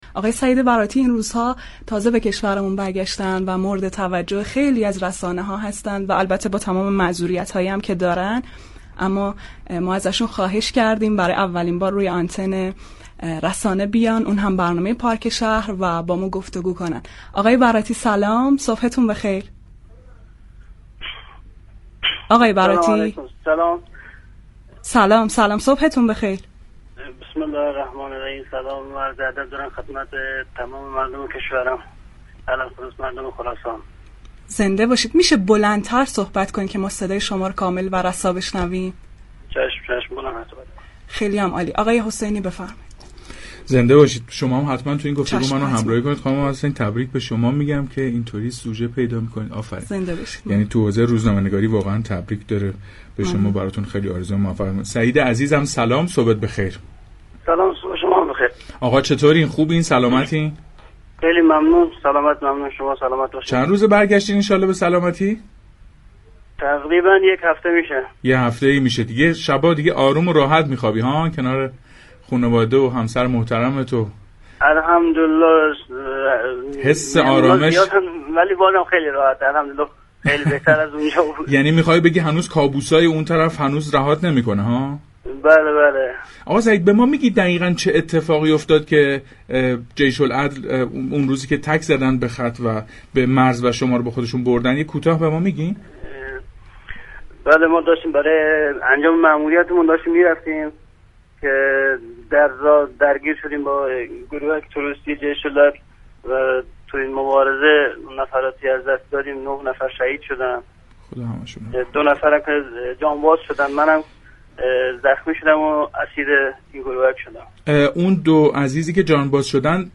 صوت| مصاحبه